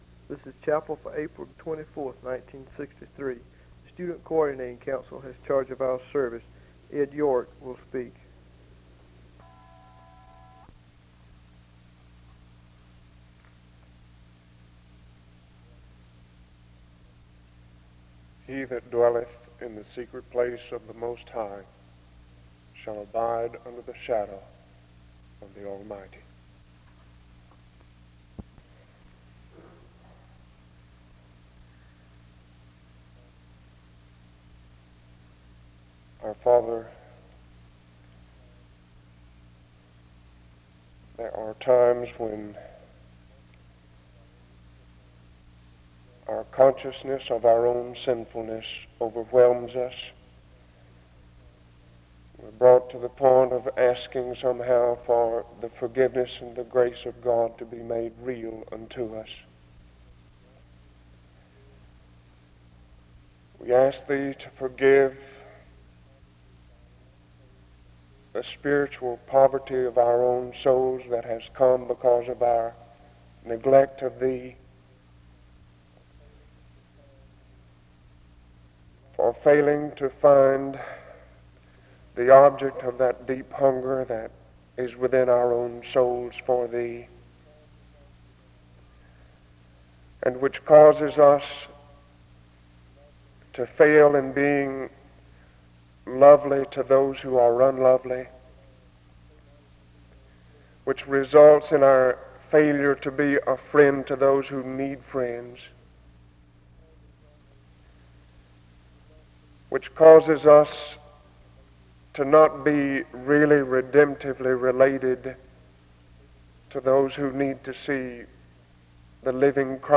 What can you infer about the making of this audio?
The service begins with the scripture reading of Psalm 91:1 and a prayer from 0:00-4:29. This service was organized by the Student Coordinating Council.